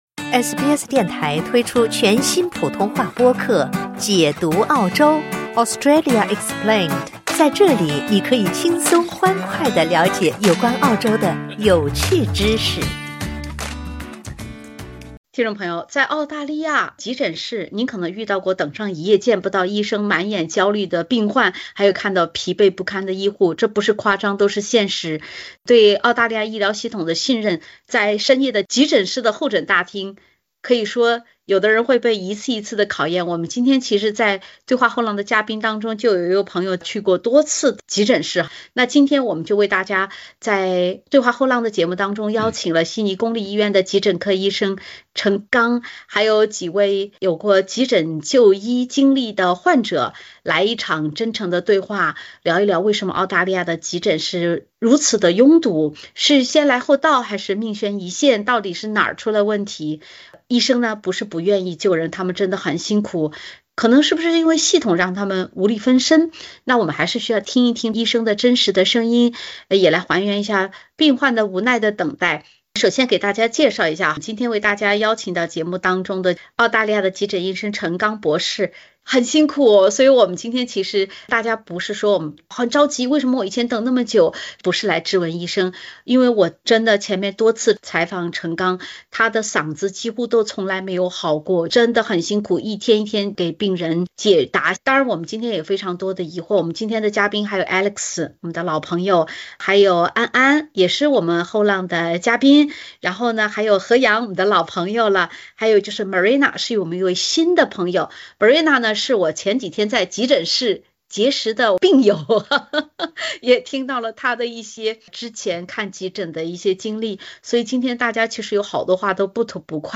澳洲急诊医生对话多位有过在澳看急诊经历的后浪嘉宾，讨论为何在澳洲就算买了私人医保，遇上看急诊，等待的时间往往还是依然“公平”地慢？